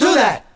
VOX SHORTS-1 0015.wav